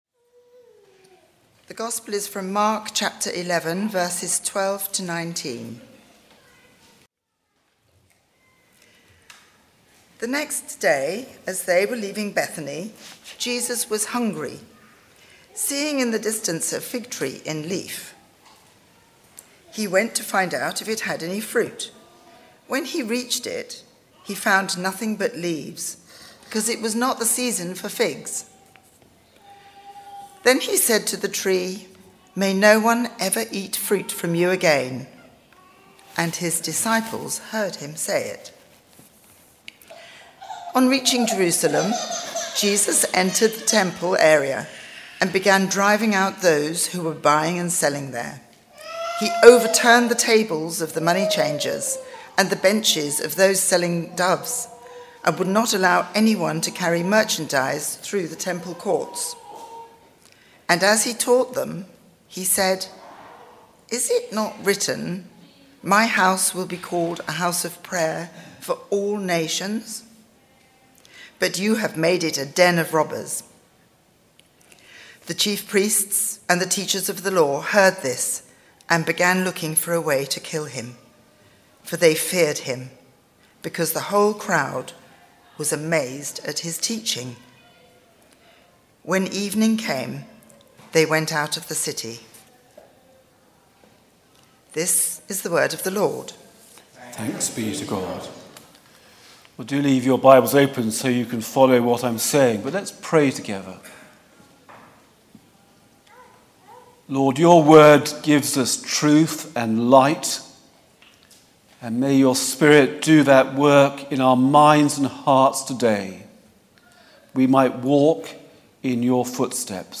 The Fruitless Fig Tree Sermon